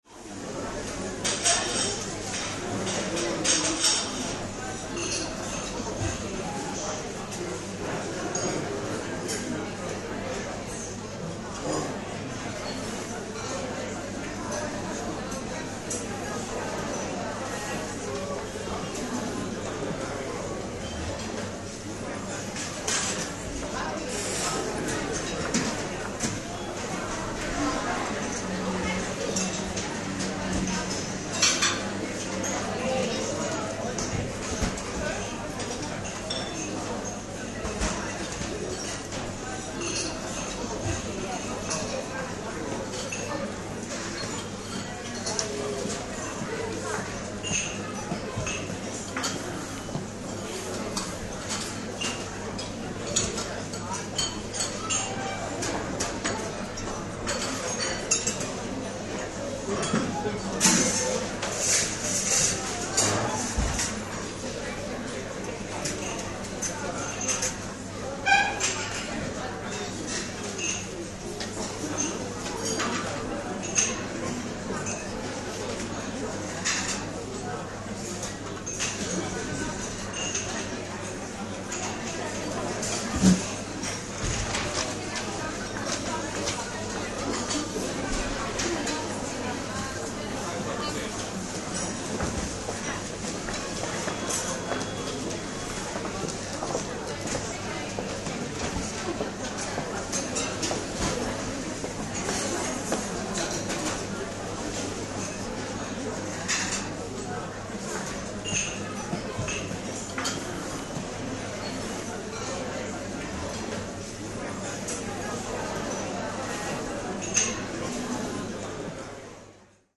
Атмосферные звуки кофейни с ароматом отменного кофе